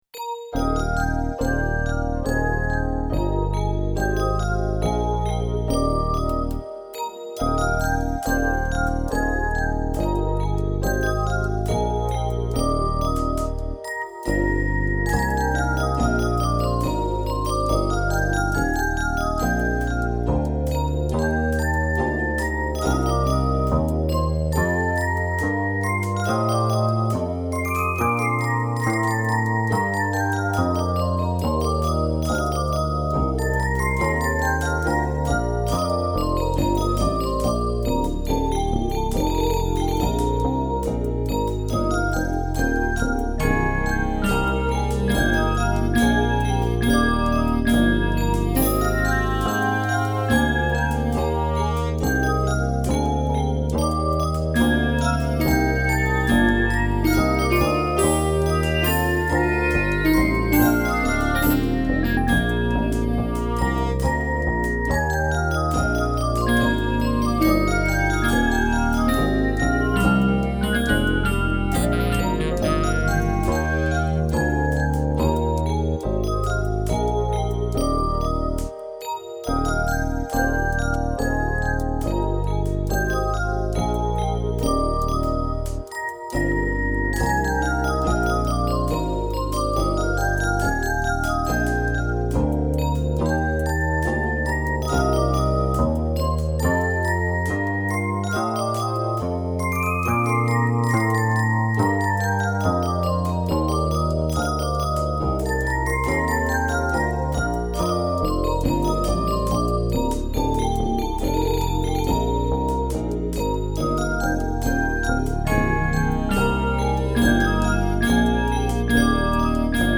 Diese Version entstand unter primitivsten Bedingungen: Eine KORG Wavestation SR und ein Doepfer-Masterkeyboard, die mir mein Cousin geliehen hatte, ein alter ATARI 1040 und eine alte Cubase-Version waren das Produktions-Setup. Leider entstand der Track unter Zeitdruck, weshalb das Timing an manchen Stellen „hinkt“. Der einfache Weg über Quantisierung wäre nur auf Kosten der Triller gegangen, deshalb tritt an manchen Stellen ein „handmade-Charakter“ deutlich hervor.
Auch vom Sound her bewegt sie sich sehr nah an gängigen Pop- und Soulsounds der frühen 1990er Jahre.